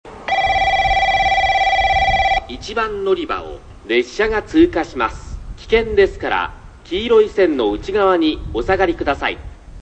スピーカー：UNI-PEX・SC-10JA（ソノコラム）
音質：C
スピーカーの位置が高く、海から抜ける風が強い日は収録には不向きでした。
１番のりば 通過放送・男性 （上り・小倉方面） (48KB/09秒)